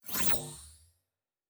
pgs/Assets/Audio/Sci-Fi Sounds/Electric/Device 4 Start.wav at 7452e70b8c5ad2f7daae623e1a952eb18c9caab4